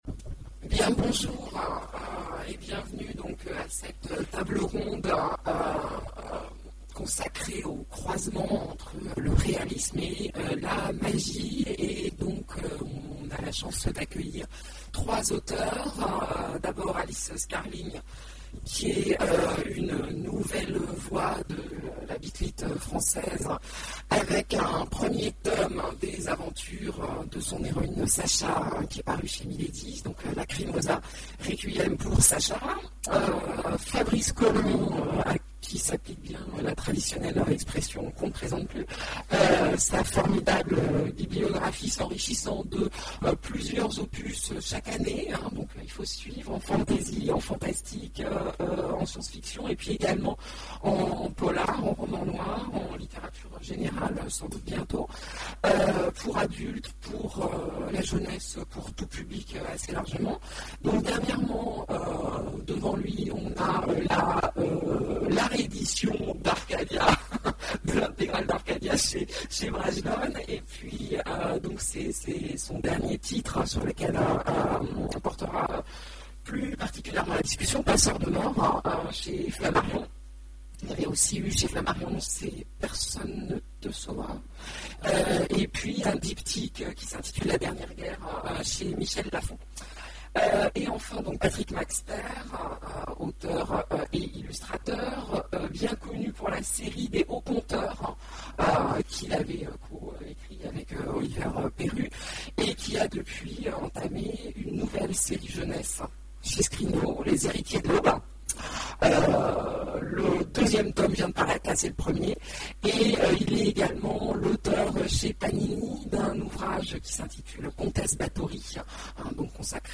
Imaginales 2014 : Conférence Fantasy, quand le réalisme croise la magie...